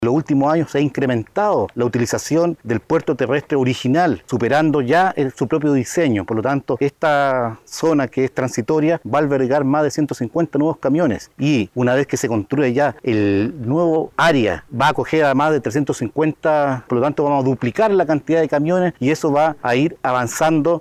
De esta manera lo destacó el subsecretario del MOP, Danilo Nuñez.